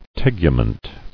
[teg·u·ment]